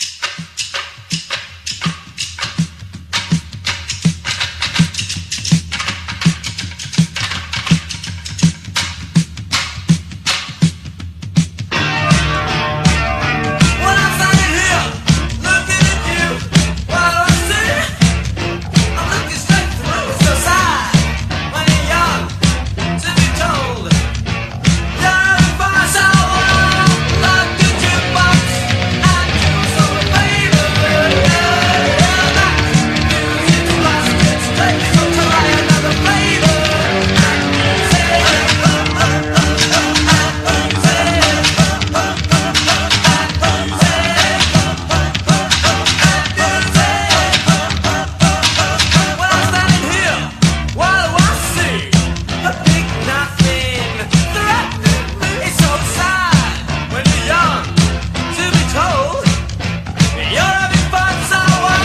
ROCK / 80'S/NEW WAVE. / NEW WAVE
個性的海賊ニューウェイヴ！